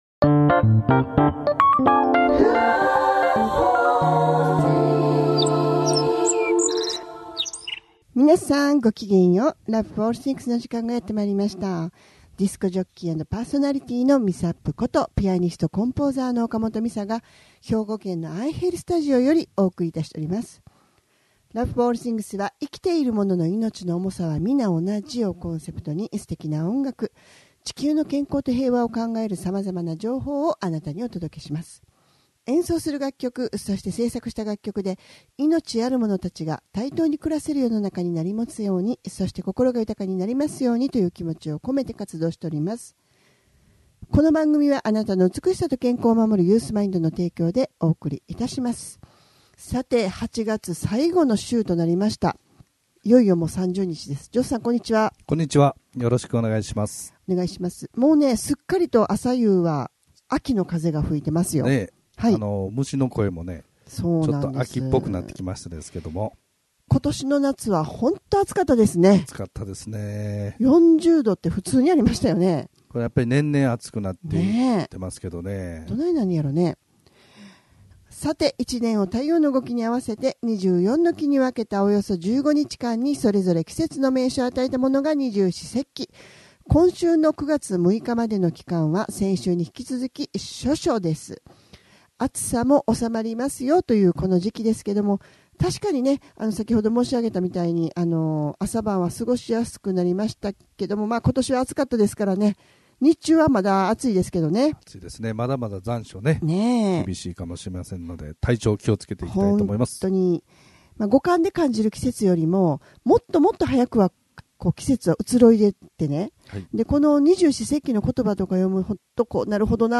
生きているものの命の重さは同じというコンセプトで音楽とおしゃべりでお送りする番組です♪